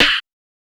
TS Snare_9.wav